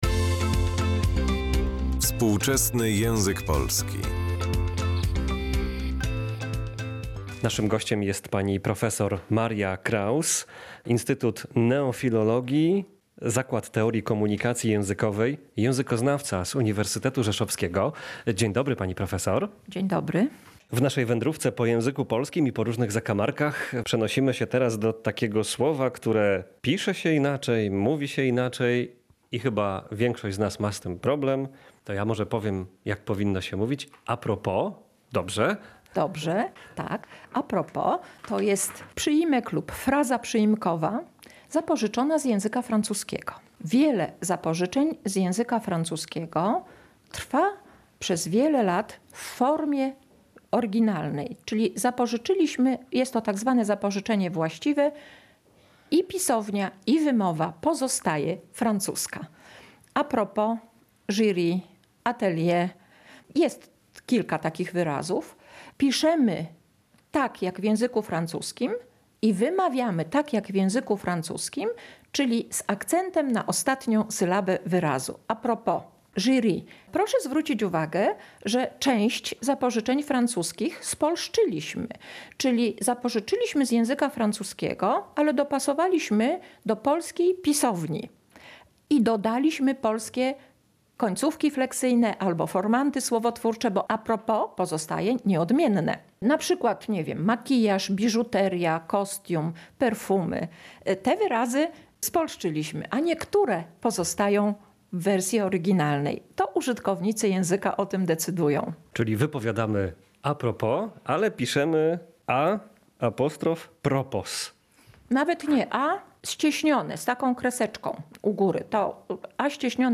Posłuchaj eksperta